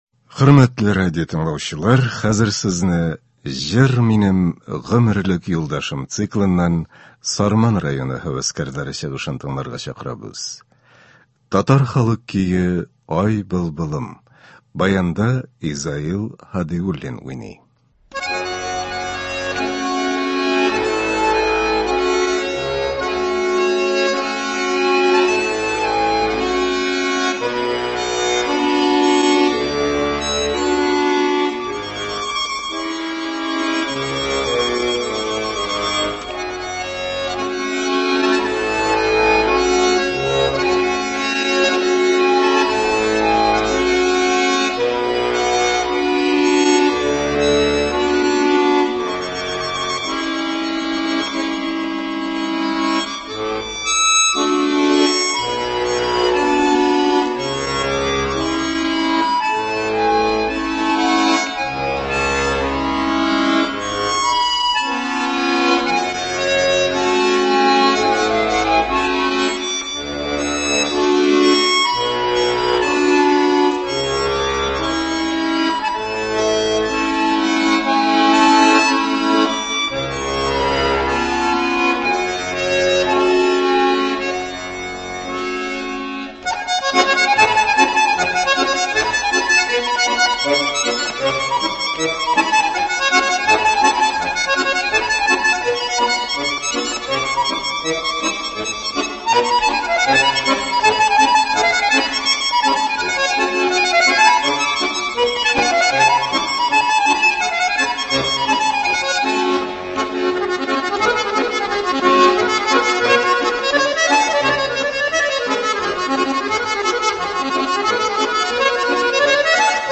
Концерт (15.05.23)